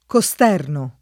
costerno [ ko S t $ rno ]